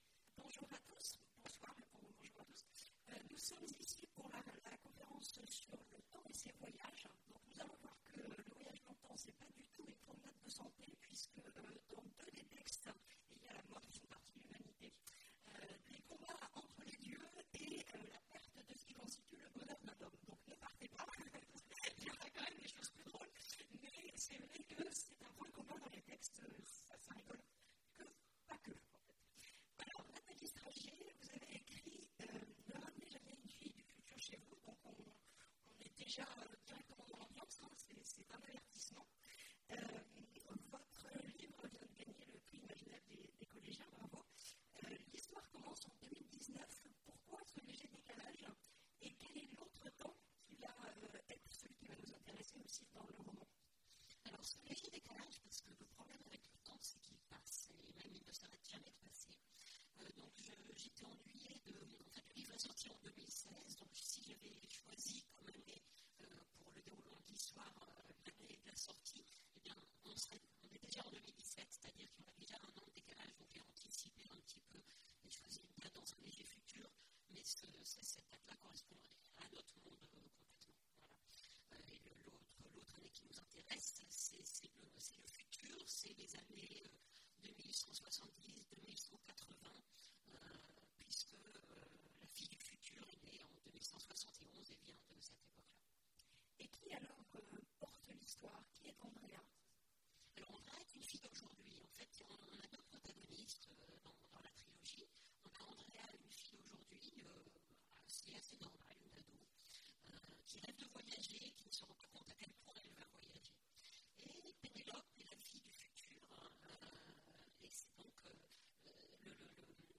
Mots-clés Voyage dans le temps Conférence Partager cet article